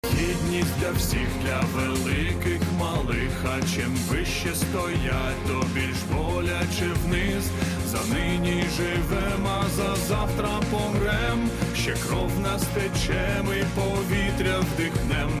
українська версія культового треку